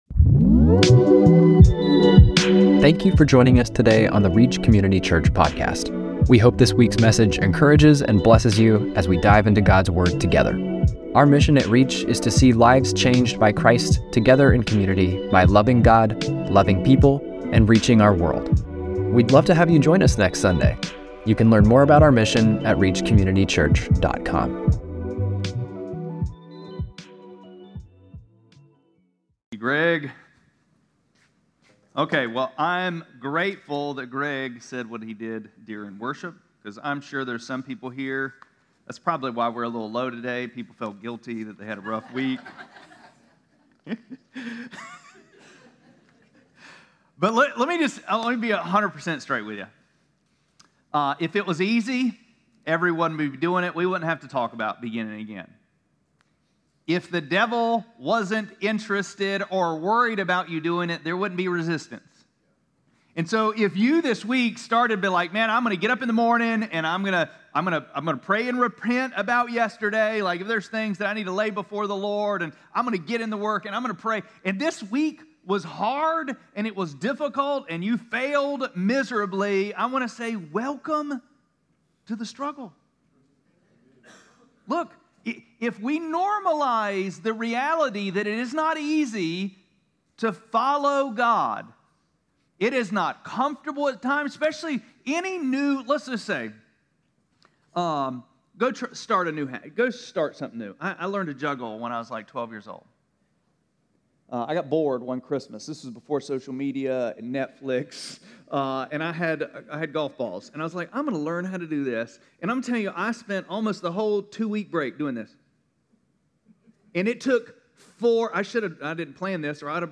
1-11-26-Sermon.mp3